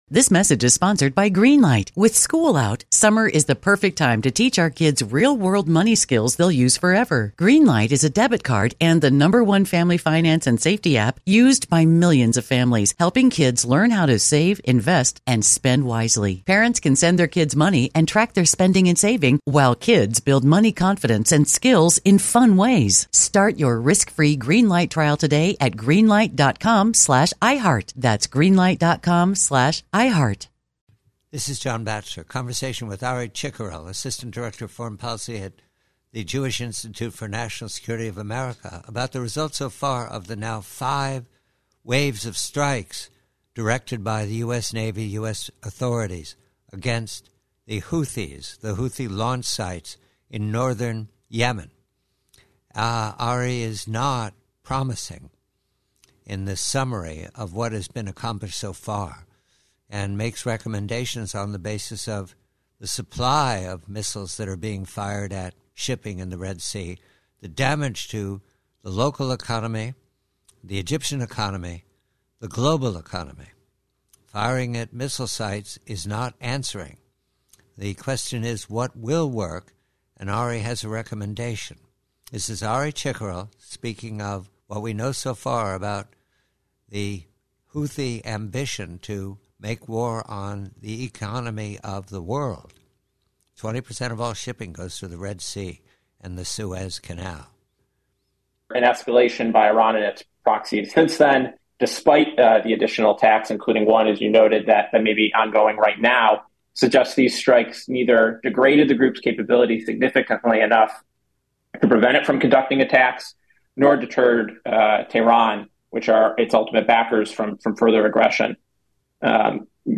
Excerpt from a conversation